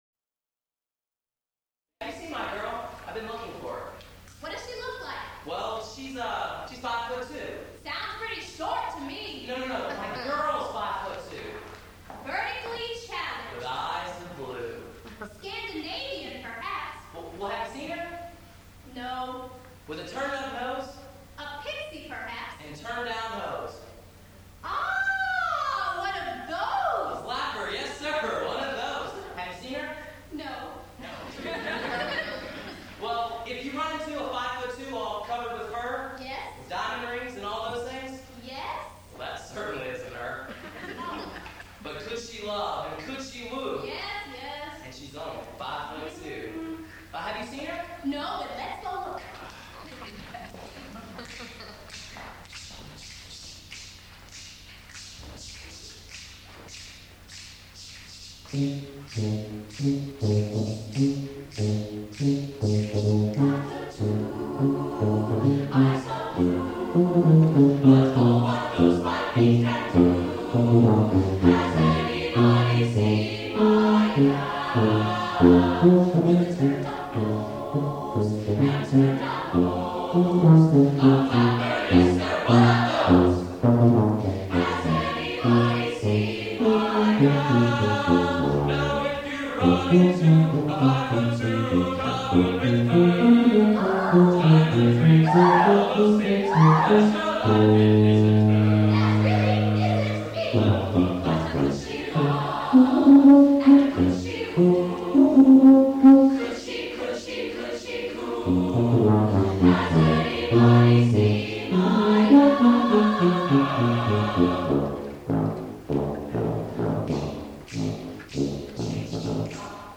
for SATB Chorus and Tuba (1998)